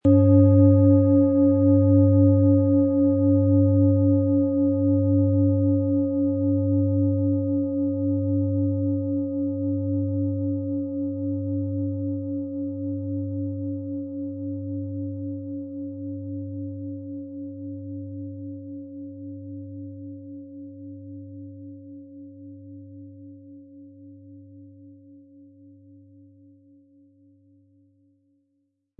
• Mittlerer Ton: Uranus
Wie klingt diese tibetische Klangschale mit dem Planetenton Pluto?
MaterialBronze